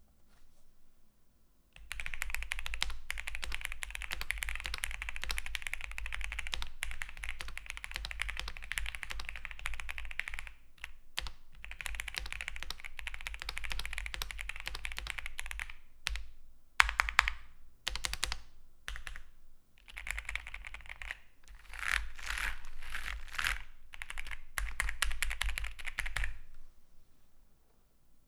Weiche Dämpfung trifft auf Alugehäuse
keychronq5max.wav